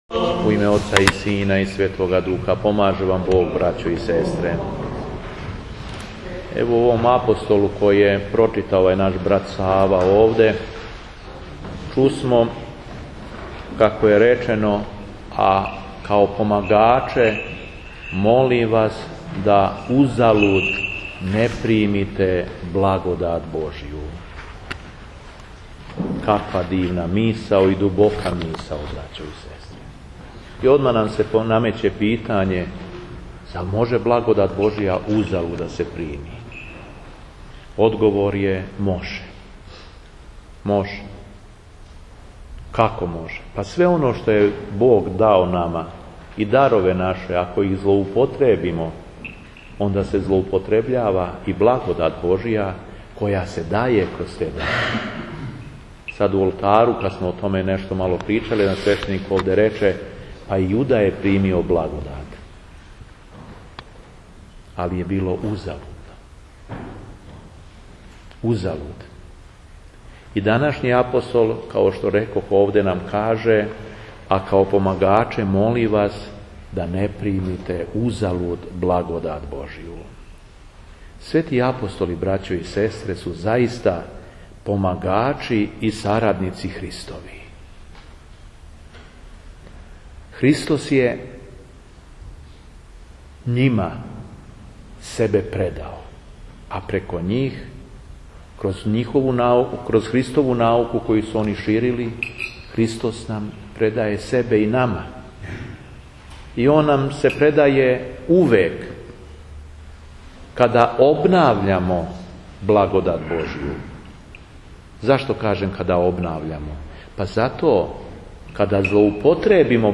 Беседа епископа шумадијског Г. Јована у Старој цркви у Крагујевцу